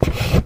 MISC Wood, Foot Scrape 09.wav